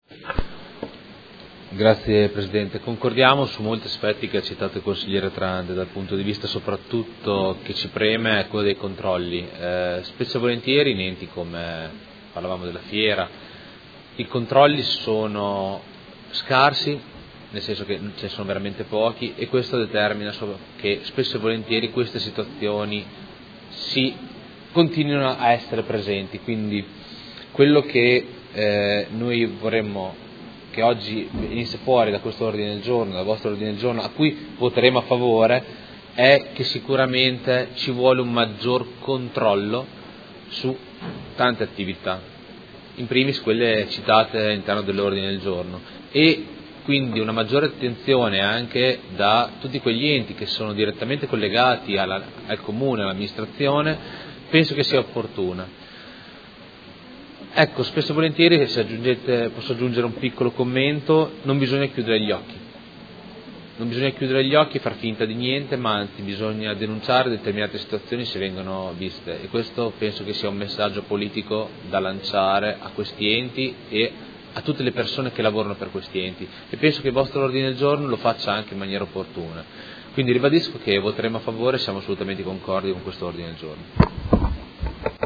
Luca Fantoni — Sito Audio Consiglio Comunale